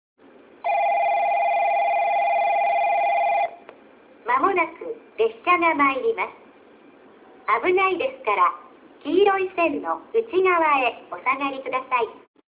1番のりば接近放送　女声 かつての放送は九州カンノ型Bでした。
スピーカーは「UNI-PEXクリアホーン」とワイドレンジ箱型がありました。